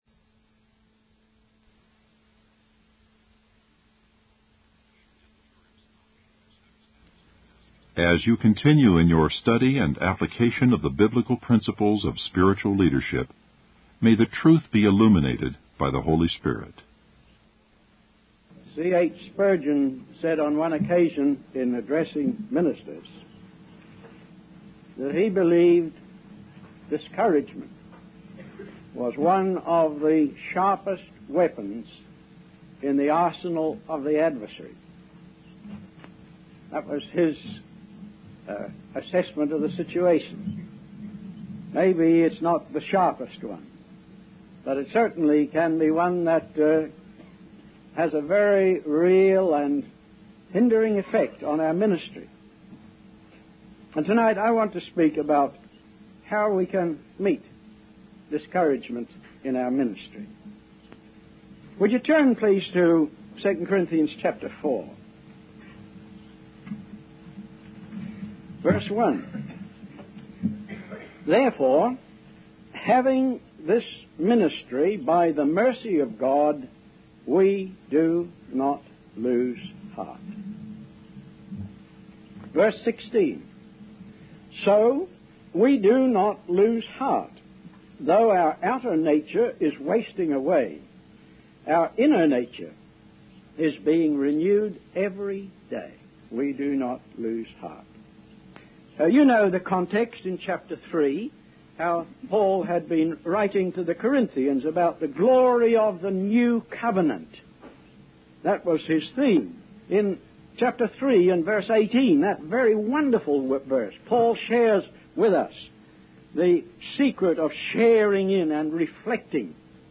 In this sermon, the speaker emphasizes the importance of focusing on the eternal rather than the temporary things of this world.